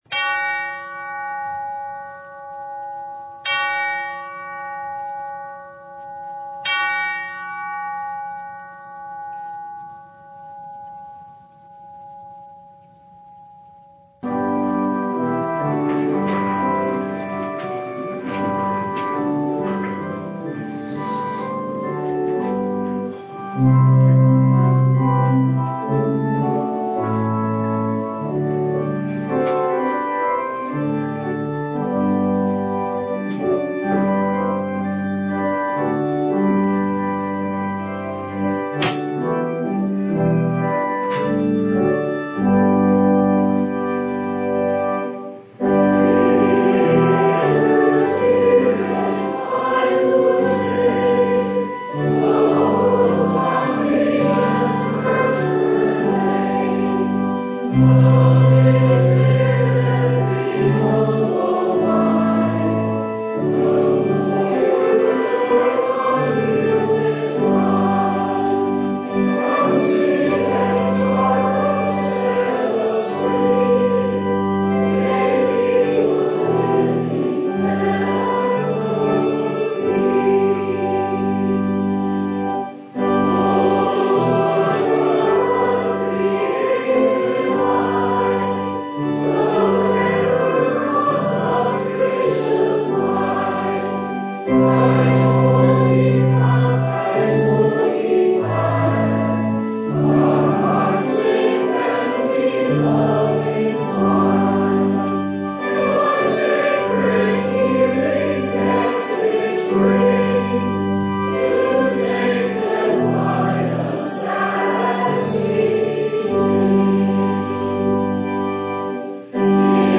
Service Type: The Feast of Pentecost